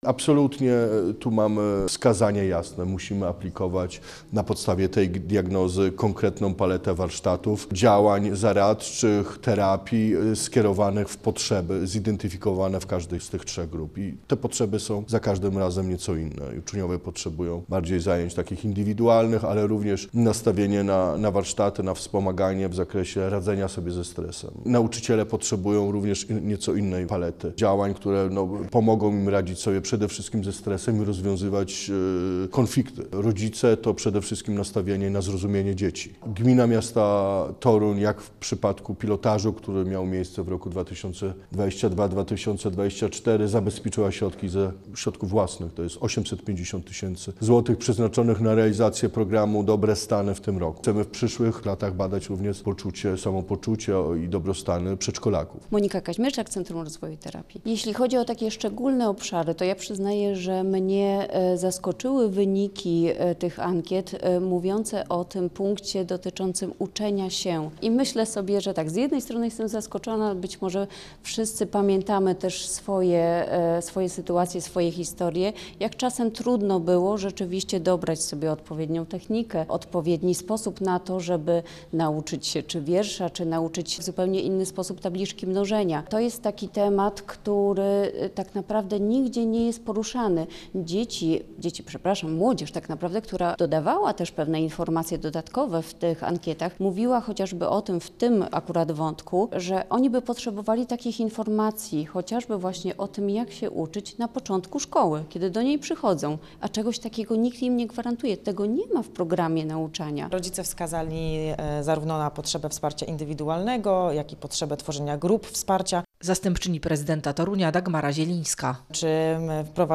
O wynikach ankiet i planowanych dalszych działaniach poinformowano podczas konferencji prasowej. 2025-05-29